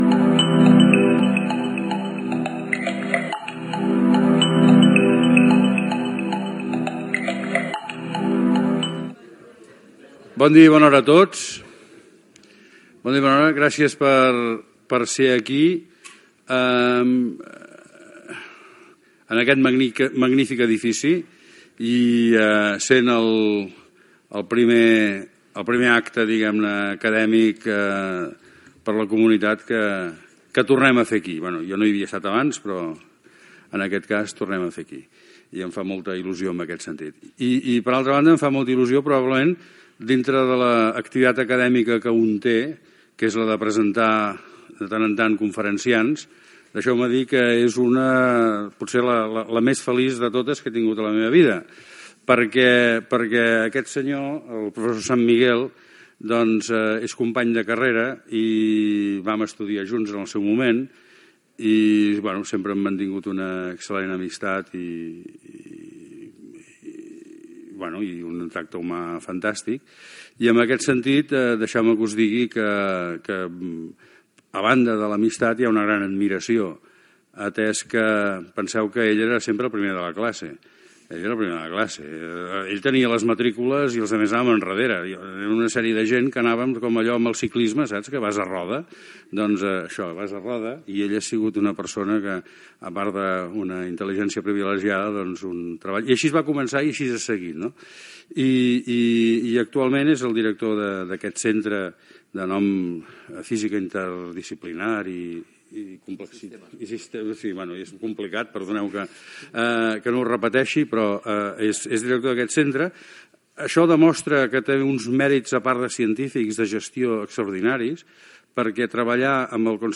Inici de la conferència